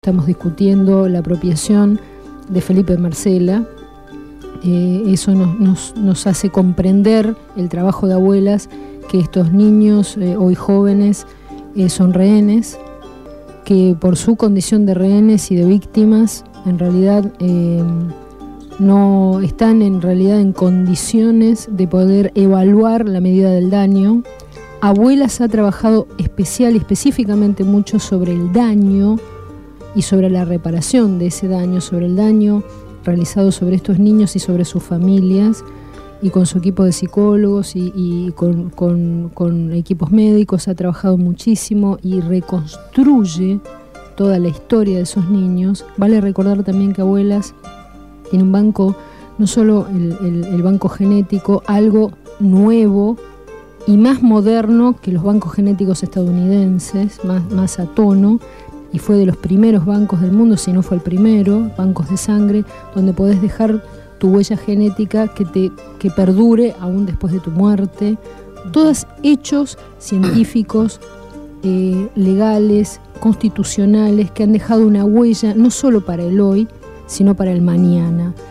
Presentamos tres fragmentos de la extensa charla que tuvo en los estudios de la radio en el programa Cambio y Futuro en el aire (jueves 20 a 22 horas).